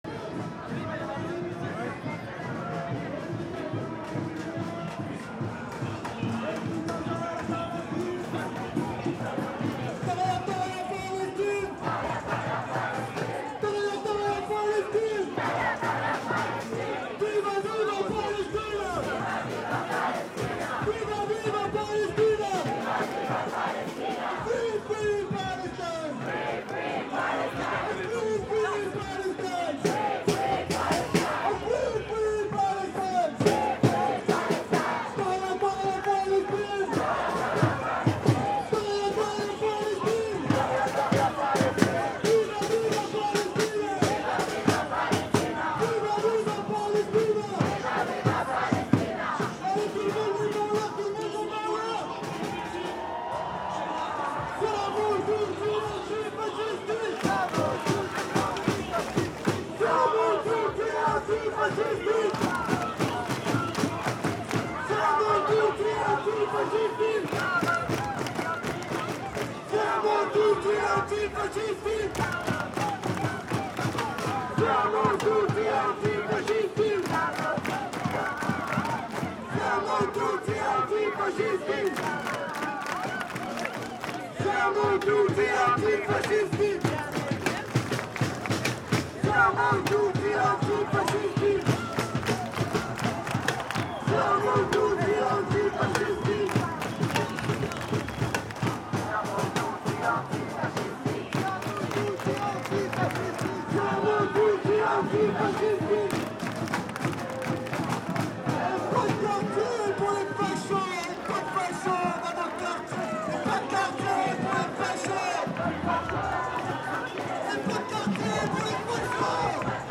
Demonstration in Paris.